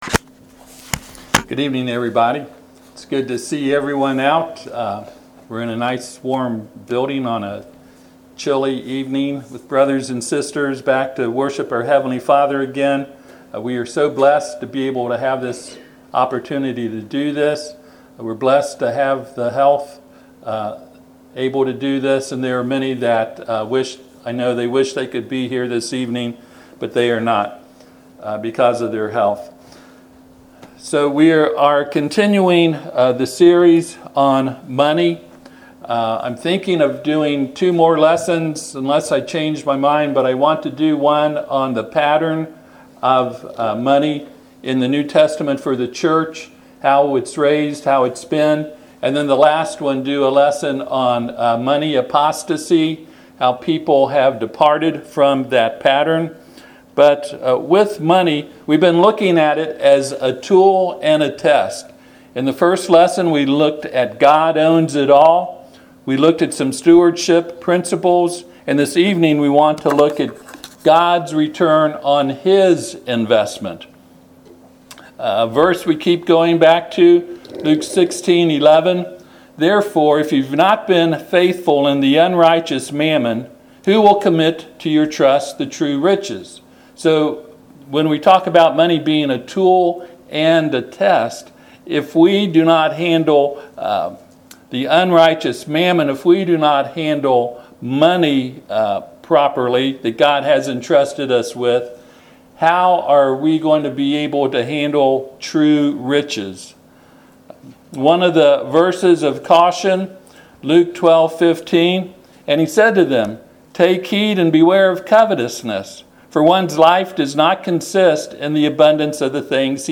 Matthew 25:28-30 Service Type: Sunday PM Money